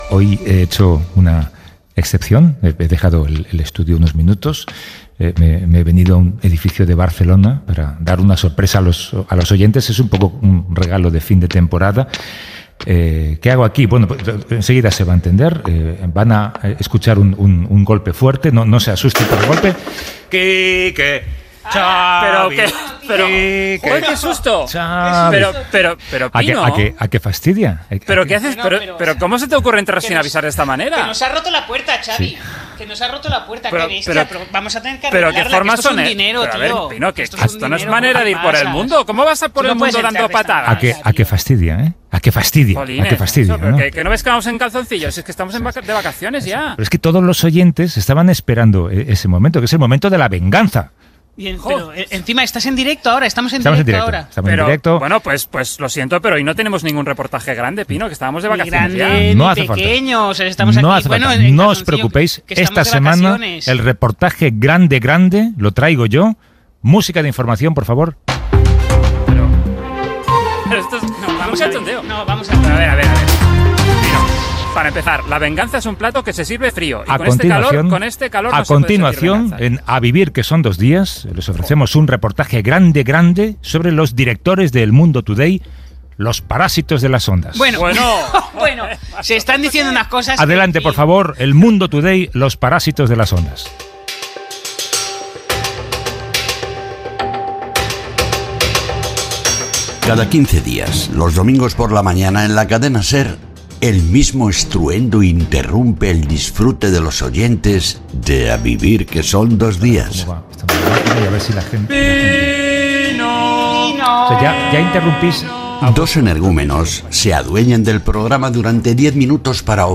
El director del programa visita la redacció de "El mundo today". Reportatge " El mundo today
Gènere radiofònic Entreteniment